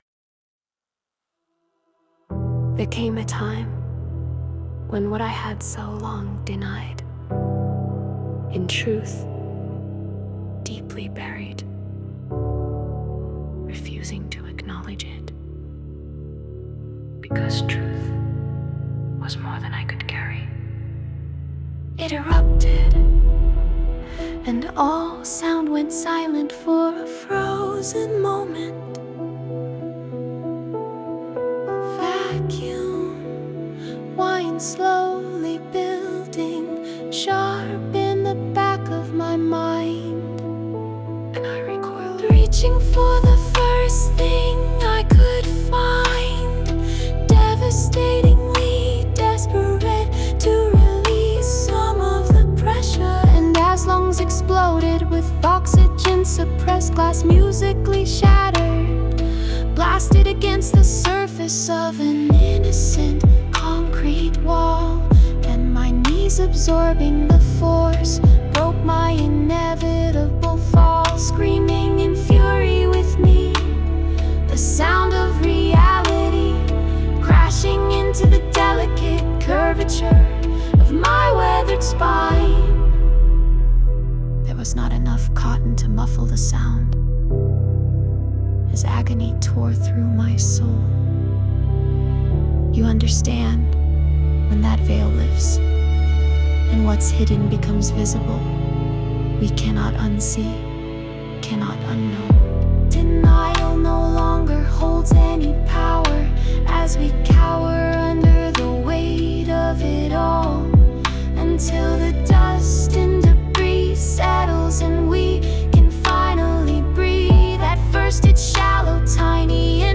The arrangement worked nicely with the words, whispers well placed, the piano was also a great choice.